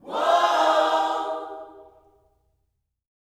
WHOA-OHS 2.wav